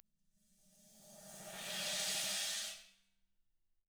Index of /90_sSampleCDs/ILIO - Double Platinum Drums 1/CD4/Partition I/RIDE SWELLD